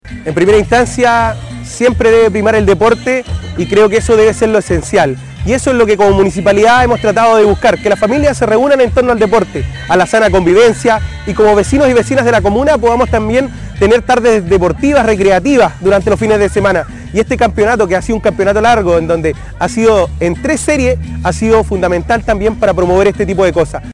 Por su parte, el concejal Edgard Ánjel recalcó la sana convivencia y el deporte que permitió este torneo durante seis meses.